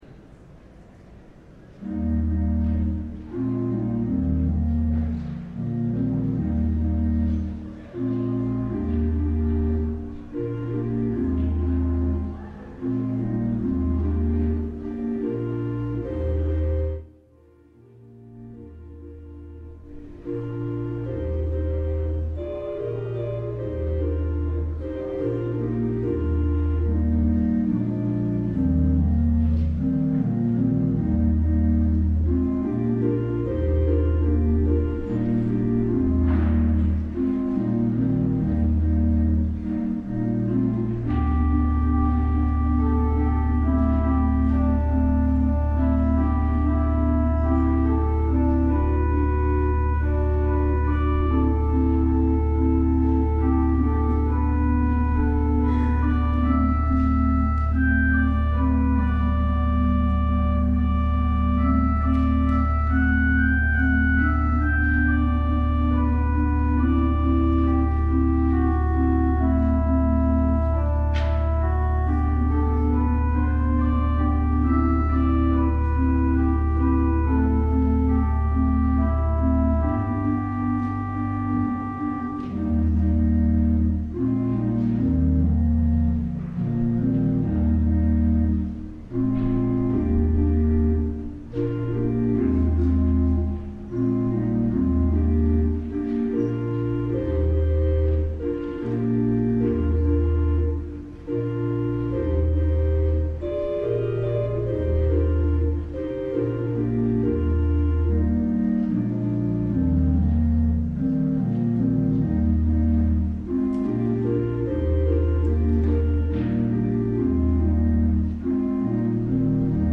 LIVE Evening Worship Service - Tips for the Tightrope